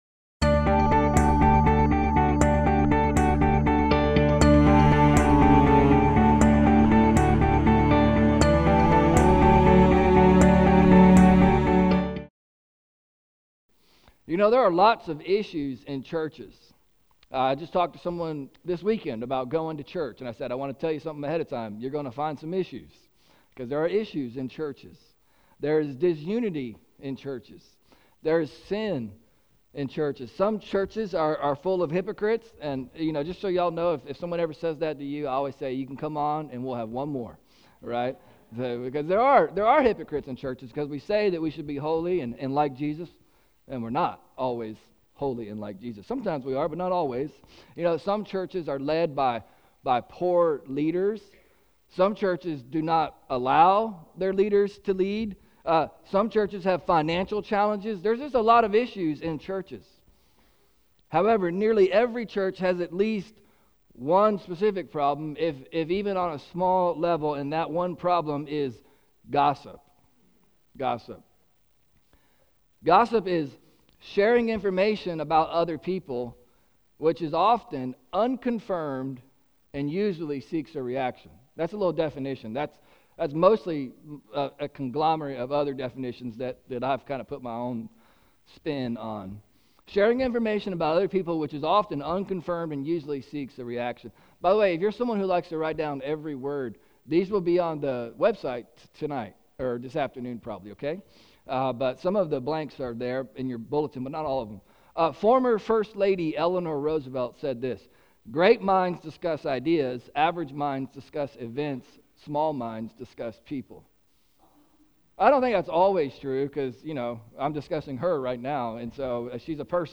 Sermon Podcasts